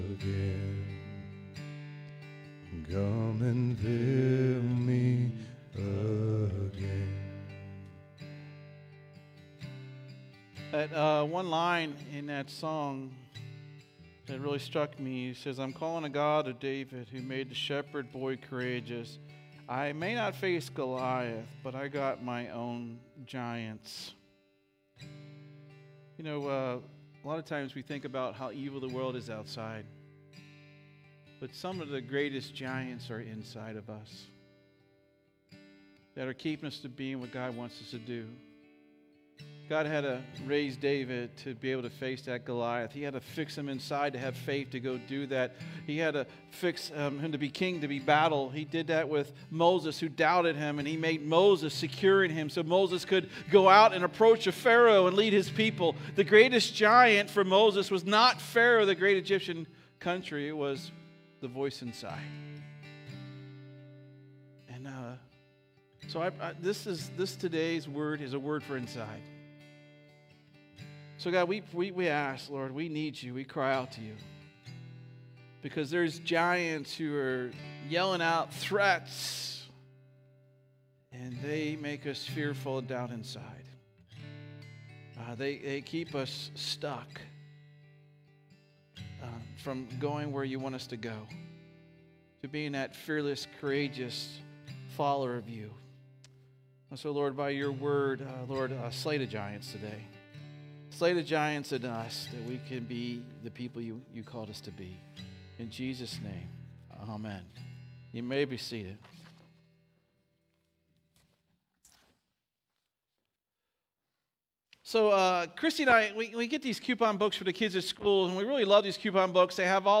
2024 Current Sermon What Jesus Requires!